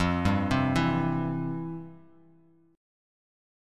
FM7sus2 Chord